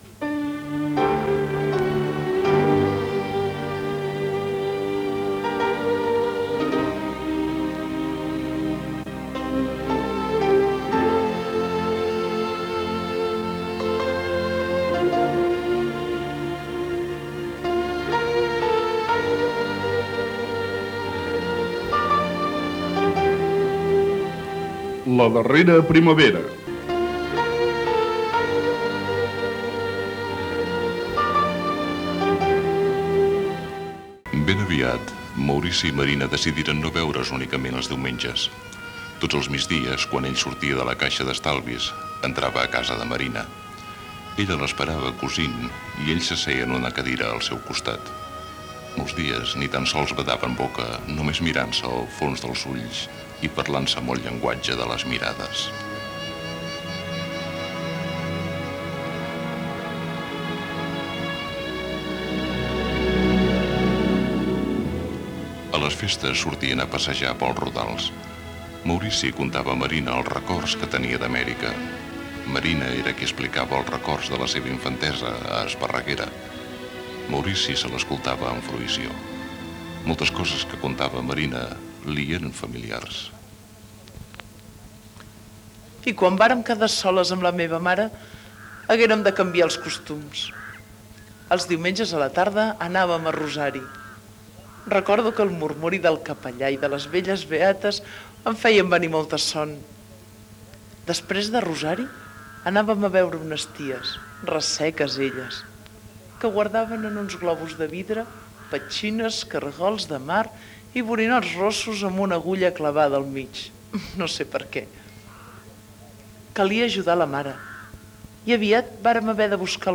Careta i fragment de la ficció sonora.
Ficció